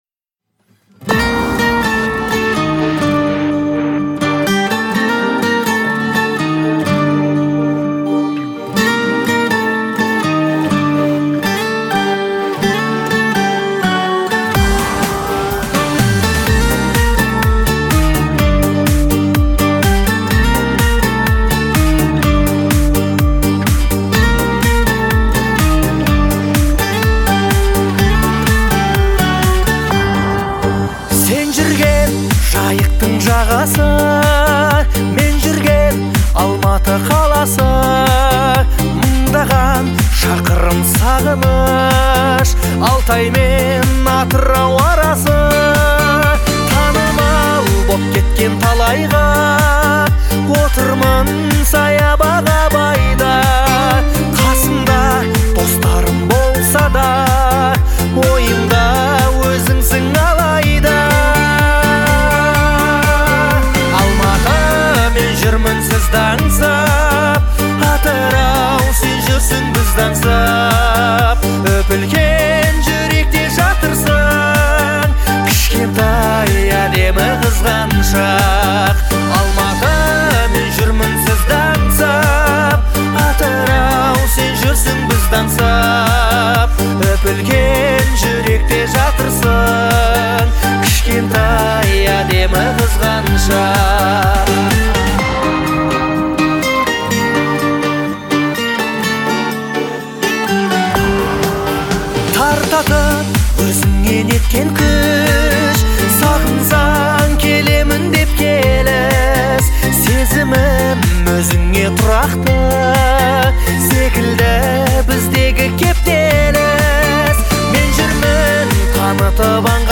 это современная казахская песня в жанре поп-фолк